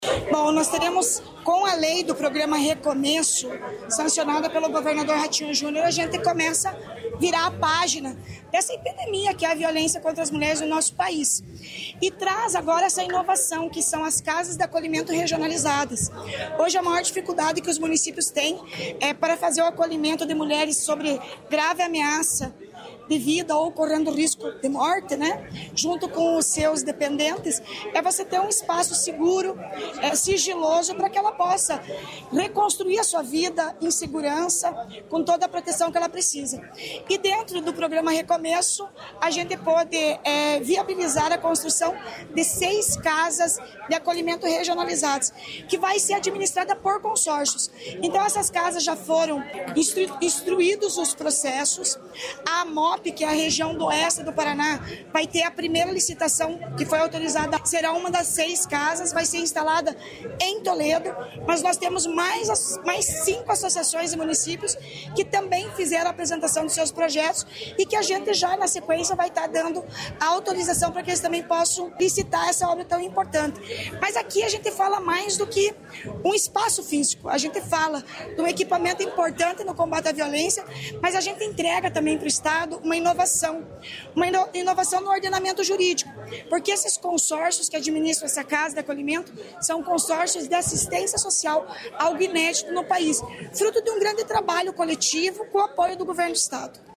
Sonora da secretária da Mulher, Igualdade Racial e Pessoa Idosa, Leandre Dal Ponte, sobre o primeiro espaço de acolhimento a mulher